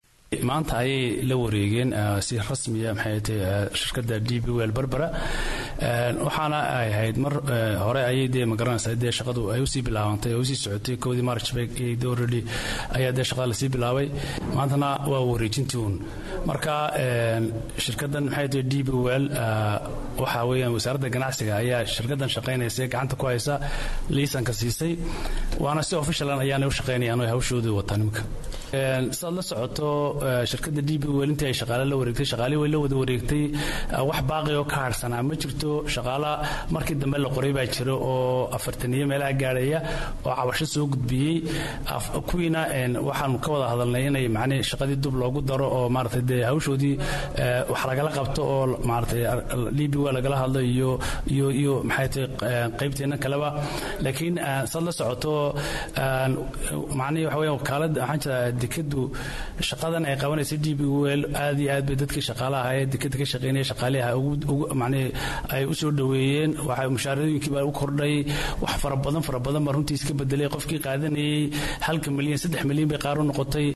Itoobiya oo saami laga siiyay dekedda Berbera iyo inta xerta Siilaanyo siiyeen. Maamulka uu Siilaanyo ku magacaaban yahay ayaa u waramay idaacada Ingiriiska ee BBCSomali sheegayna saamiga la siiyay.